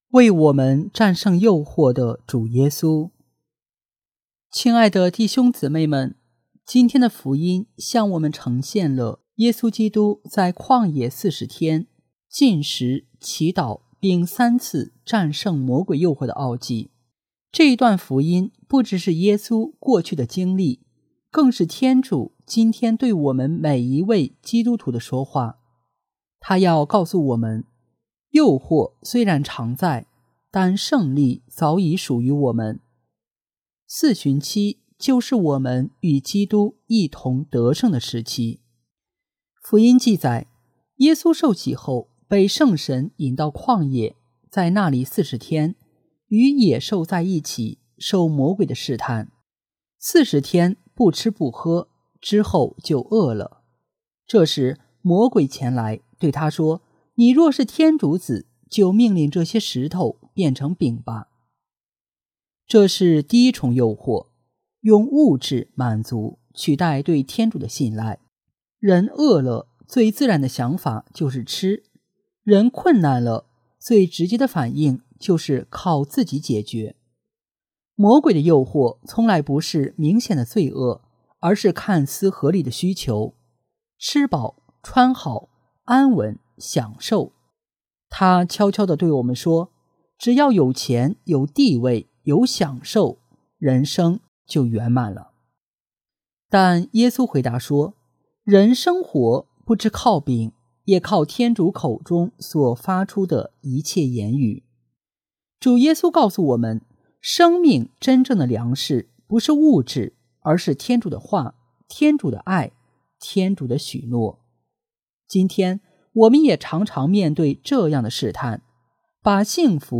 为我们战胜诱惑的主耶稣（四旬期第一主日讲道词）